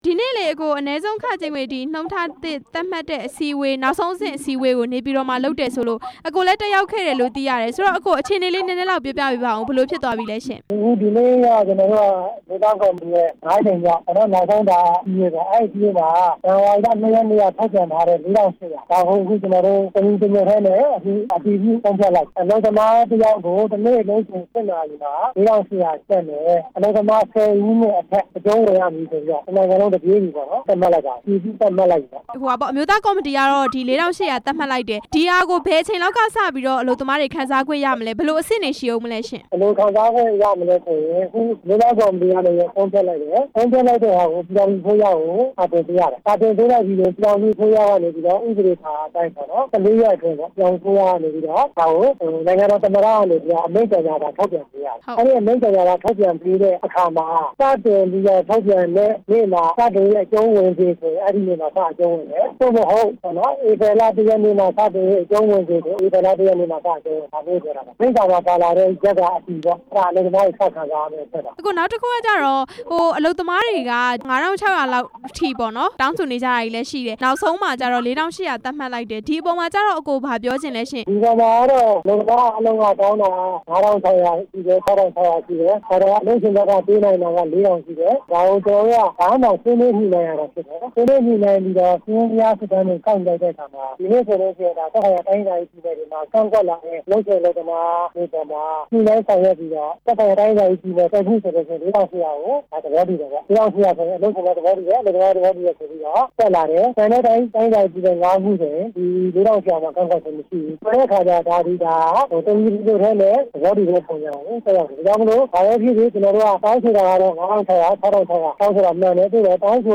တစ်ရက်လုပ်ခ ကျပ် ၄၈၀၀ သတ်မှတ်လိုက်တဲ့အကြောင်း မေးမြန်းချက်